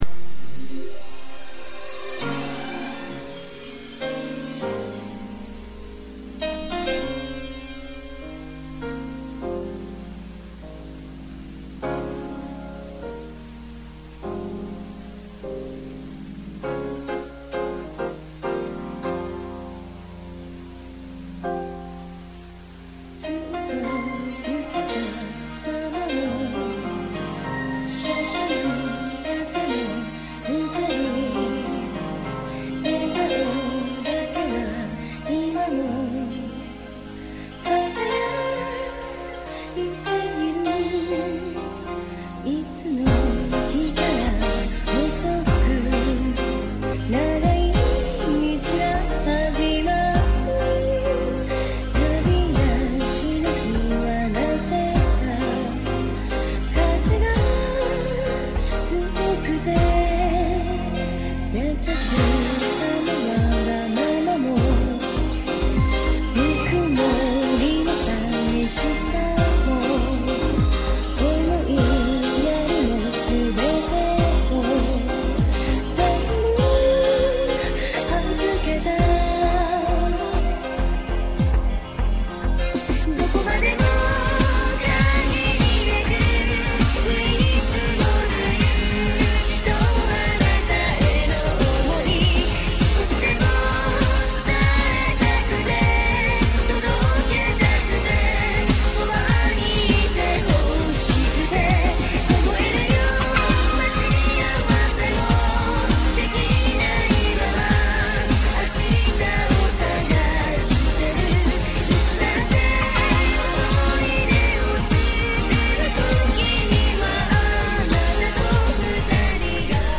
( 主唱兼鍵盤 )
( 女主唱 )
( RAPPER )